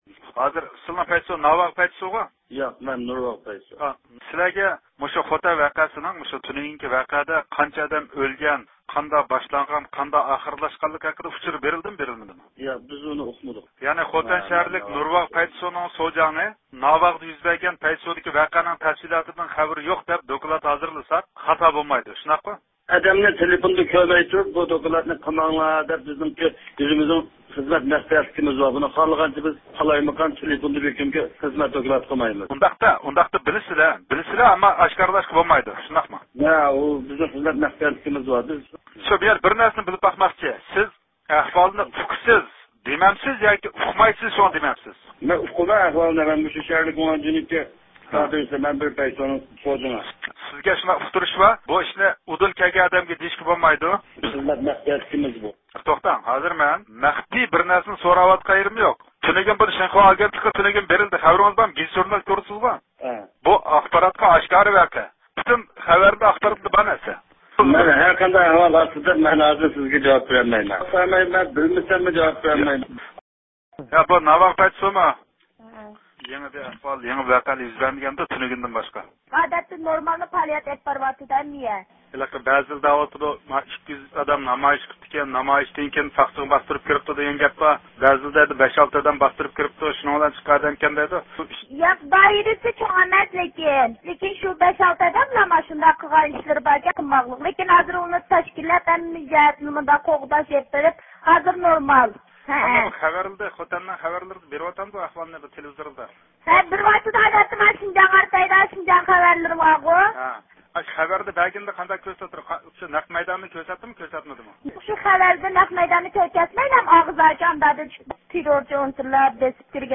دۇنيا ئۇيغۇر قۇرۇلتىيى رەئىسى رابىيە خانىم بۇ ھەقتە ئىستانسىمىزنىڭ مەخسۇس زىيارىتىنى قوبۇل قىلدى.
دۇنيا ئۇيغۇر قۇرۇلتىيىنىڭ رەئىسى رابىيە قادىر خانىم، خوتەندە يۈز بەرگەن ۋەقە ھەققىدە زىيارىتىمىزنى قوبۇل قىلىپ، خىتاي ھۆكۈمىتىنى «5-ئىيۇل ۋەقەسىدىن ئىبرەت ئالمىدى، باستۇرۇشلارغا نارازى بولۇپ تىنچلىق ئۇسۇلدا ئىپادە بىلدۈرگەن ئۇيغۇرلارنى ئوققا تۇتۇپ، قانلىق قىرغىنچىلىق بىلەن دۆلەت تېررورلۇقى يۈرگۈزمەكتە…» دەپ ئەيىبلىدى.